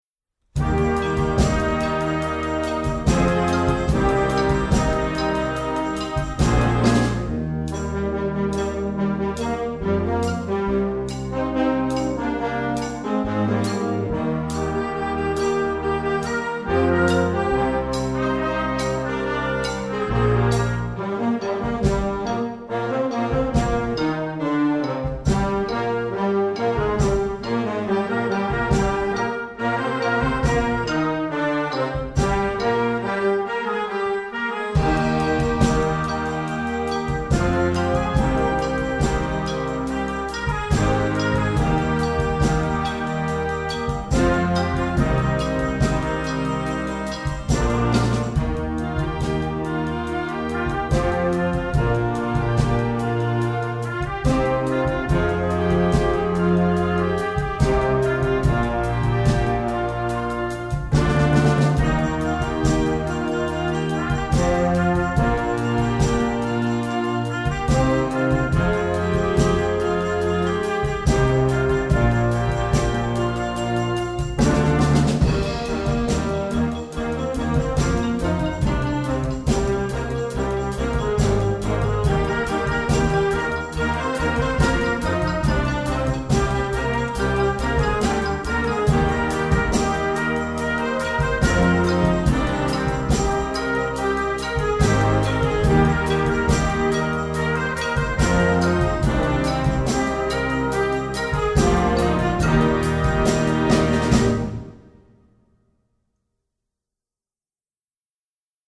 Gattung: Modernes Jugendwerk
Besetzung: Blasorchester